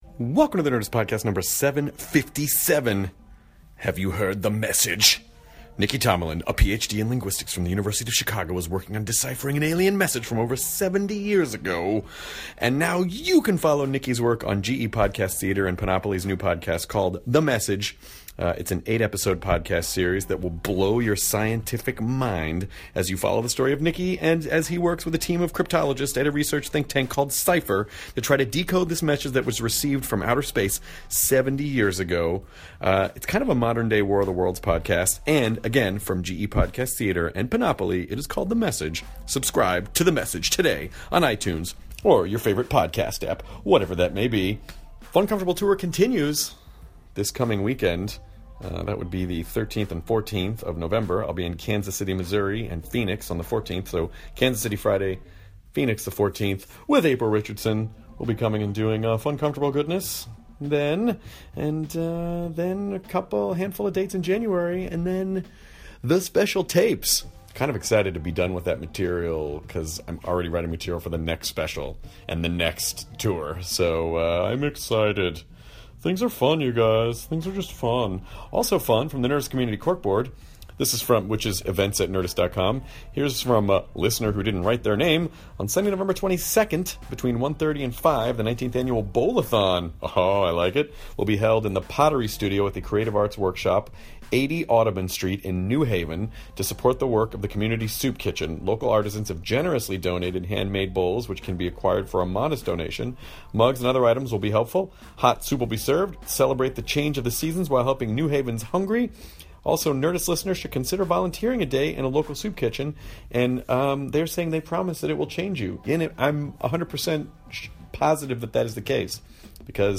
marrying his co-star Anna Paquin and he reads a book in his impression of a Welsh accent. He also talks about his experience doing live theater, being a father of twins and The Bastard Executioner on FX!